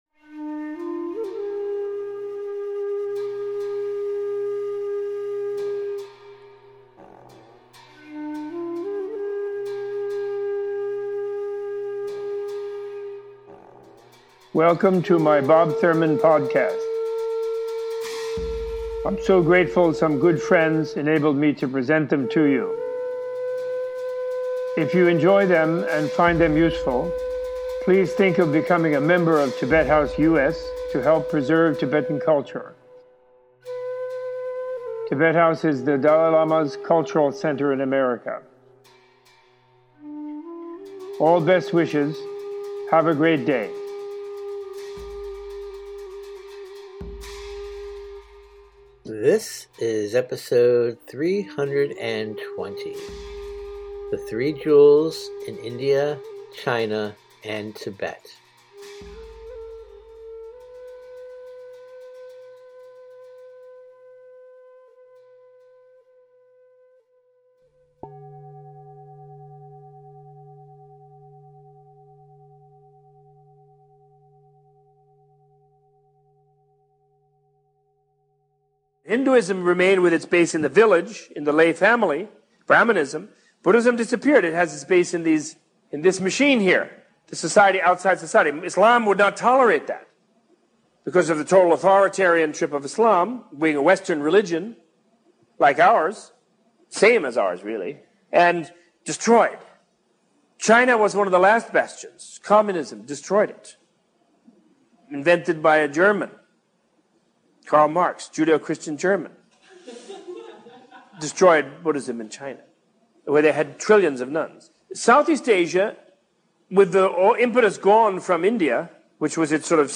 Opening with a discussion of the disappearance of Buddhism in India, Robert Thurman gives a teaching on the history of the Buddhist community and the flowering of the Buddha’s teachings in Tibet. This podcast includes an extended discussion on the influence of China and Chinese Communism upon Buddhism in Tibet and on Tibetan culture.